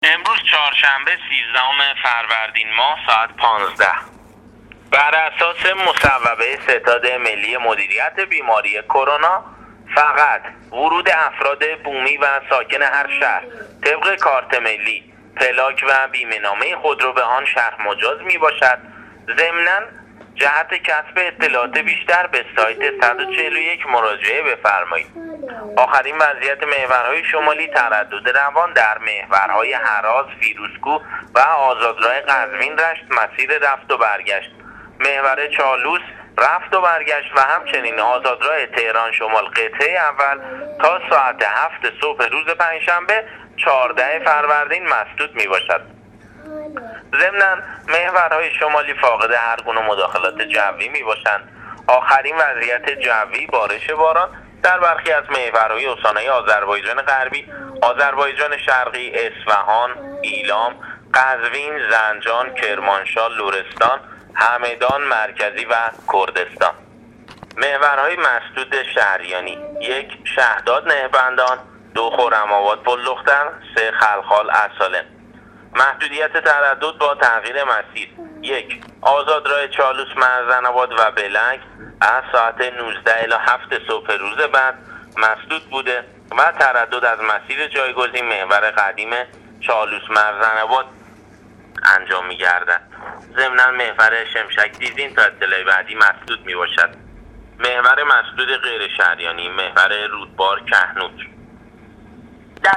گزارش رادیو اینترنتی از آخرین وضعیت ترافیکی جاده‌ها تا ساعت۱۵ سیزدهم فروردین